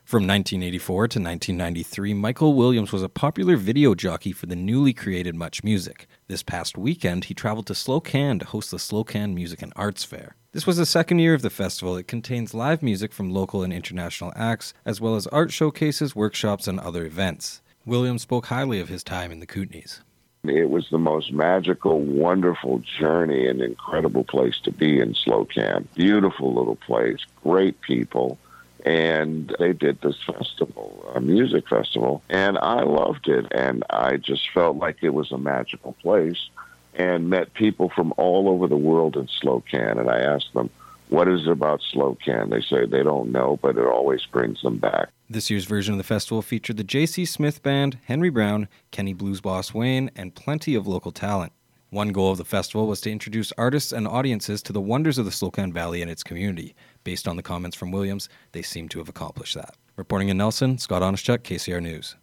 While in the area, Williams spoke with Kootenay Morning about the fair in Slocan, his career and more.